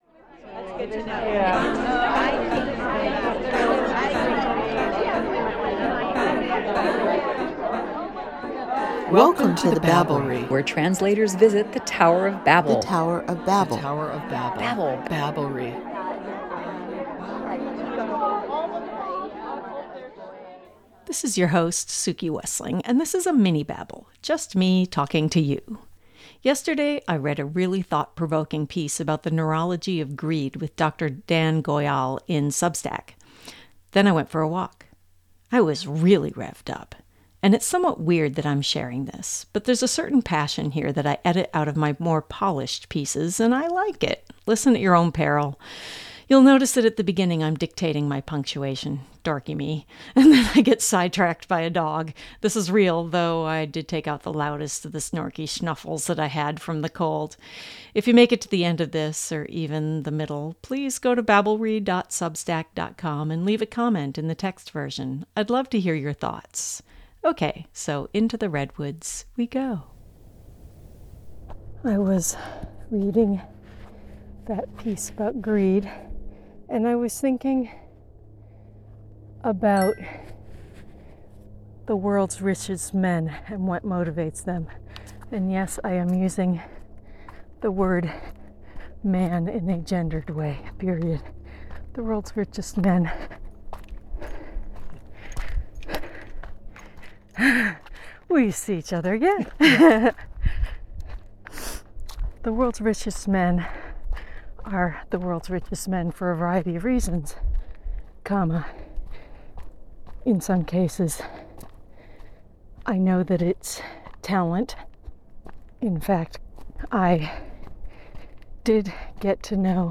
This is an unusual version of “Just me, talking to you.” In this case, I recorded my thoughts while walking in the redwoods.